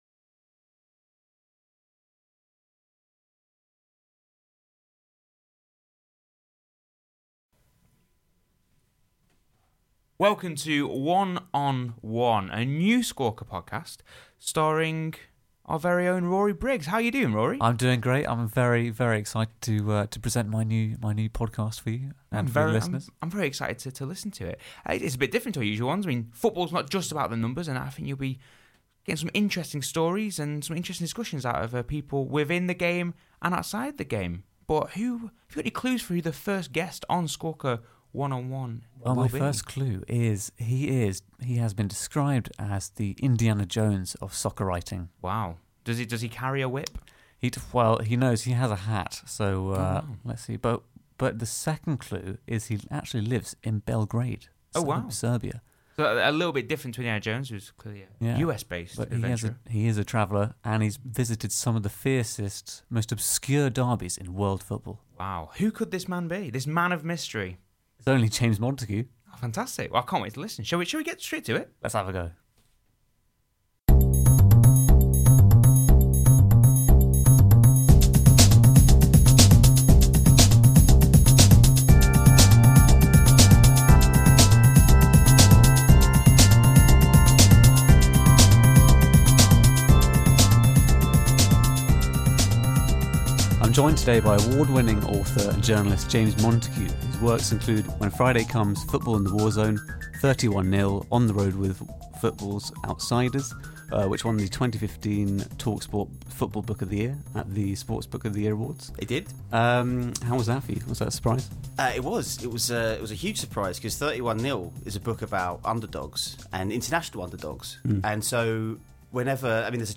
interview his guest about their love for football and the personal history they have with the game.